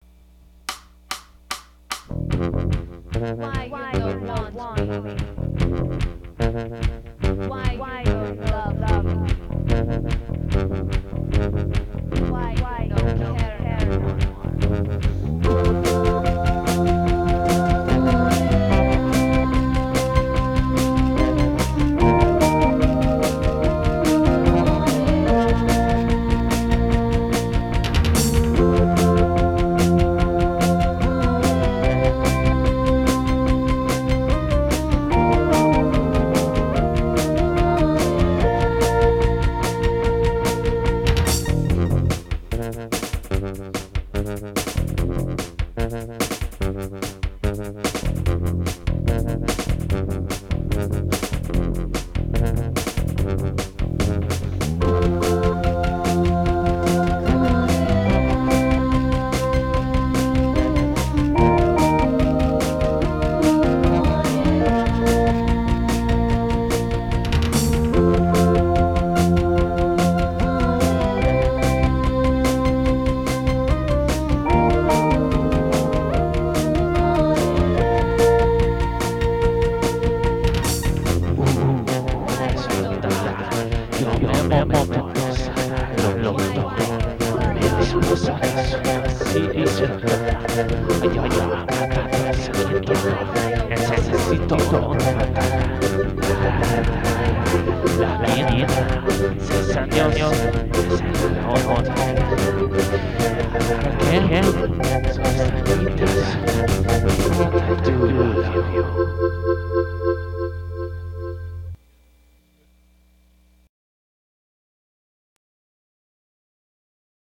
Yamaha RY10, Moog, Hammond, Vocals
Vocals
Bass